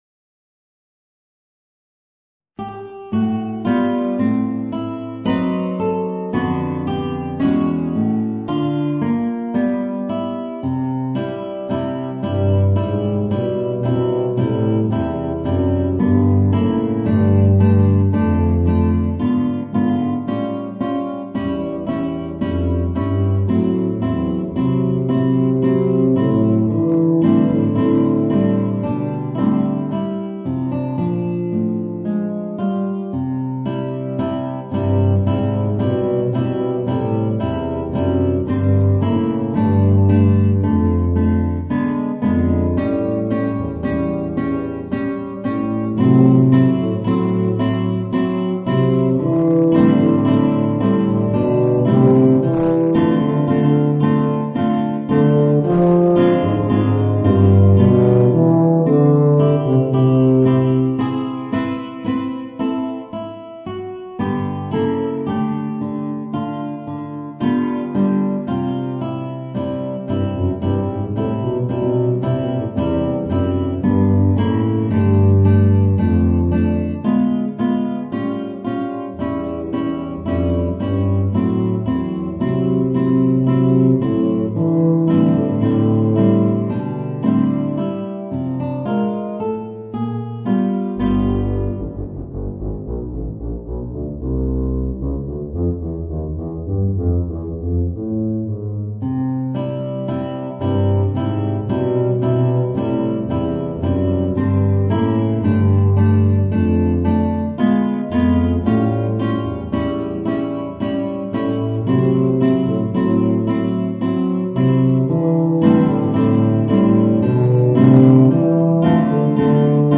Voicing: Guitar and Tuba